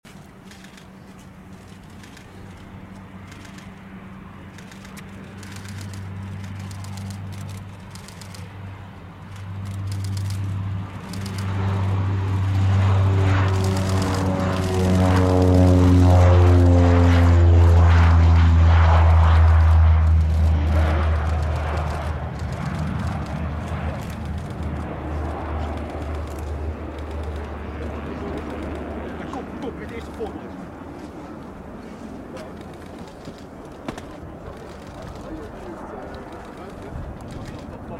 Ukraine Air Force Antonov AN 26 sound effects free download
Ukraine Air Force Antonov AN-26 Take Off Rotterdam Airport